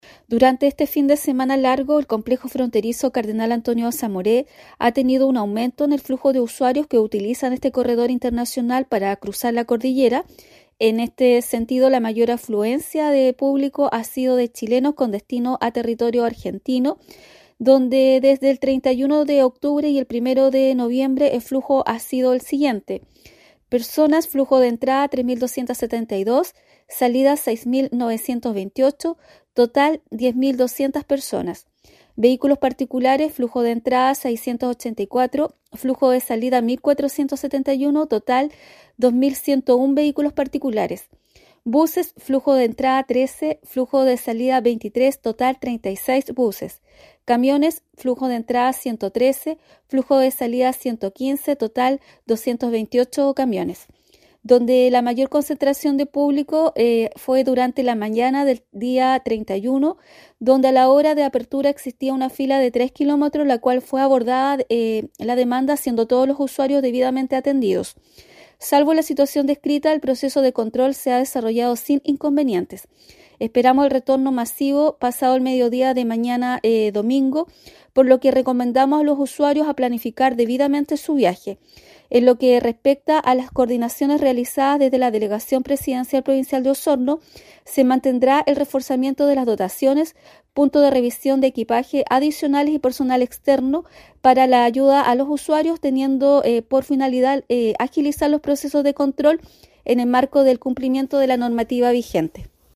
Esta mañana, la Delegada Presidencial Provincial de Osorno, Claudia Pailalef Montiel, informó sobre el estado de situación en el Complejo Fronterizo Cardenal Antonio Samoré, señalando que se realiza un monitoreo constante de la situación, con refuerzos en las dotaciones de servicios contralores y todas las ventanillas de atención habilitadas.